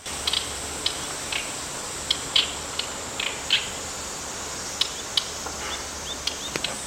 有難いことに地鳴きとぐぜりも披露してくれた。
オオヨシキリの声
さえずり一歩手前まで鳴いていた